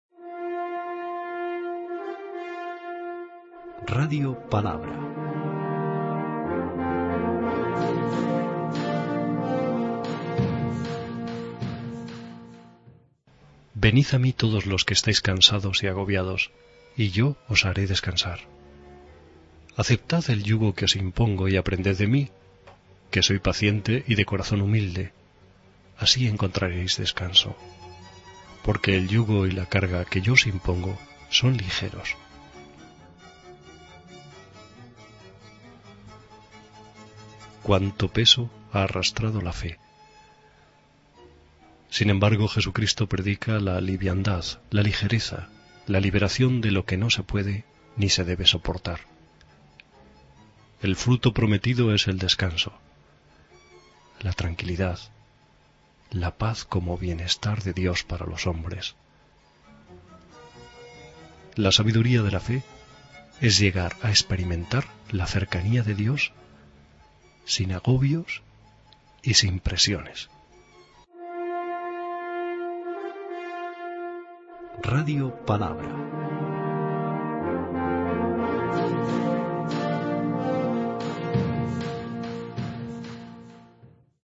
Audio y comentario del Evangelio de hoy (I)